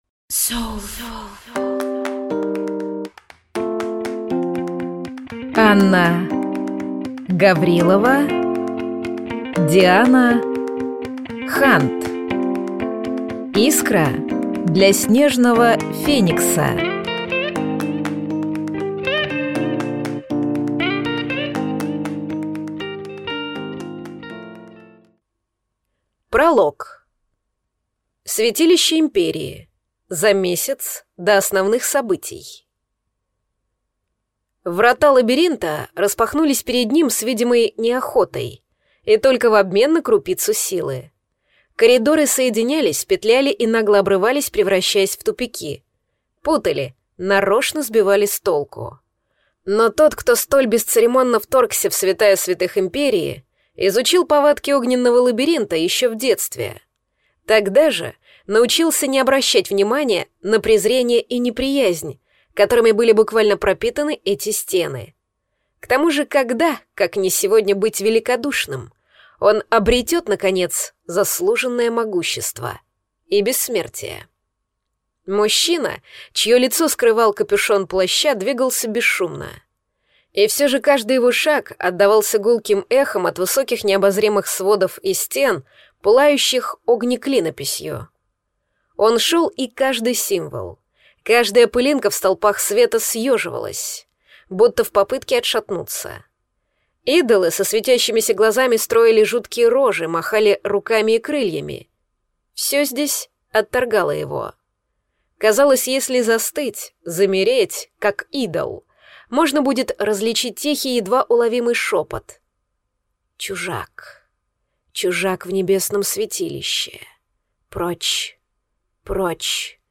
Аудиокнига Искра для Снежного феникса | Библиотека аудиокниг